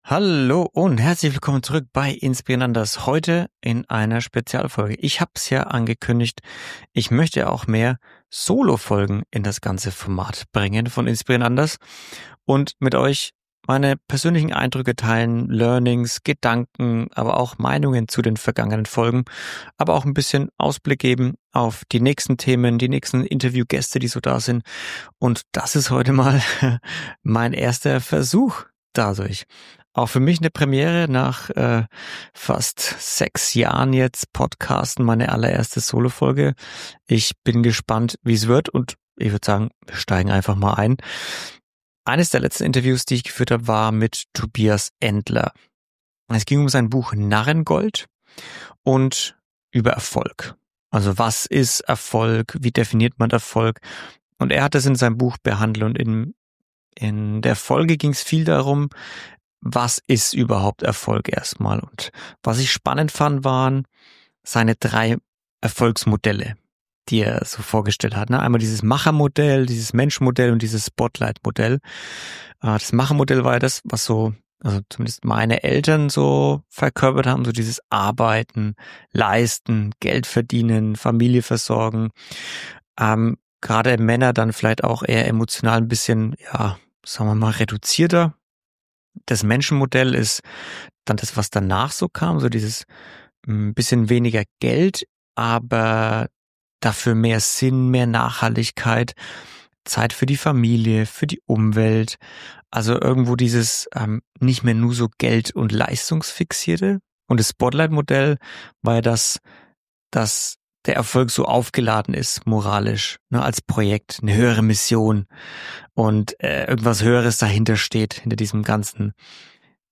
In dieser Solo-Folge nehme ich dich mit hinter die Kulissen von drei Gesprächen, die mich in den letzten Wochen beschäftigt haben.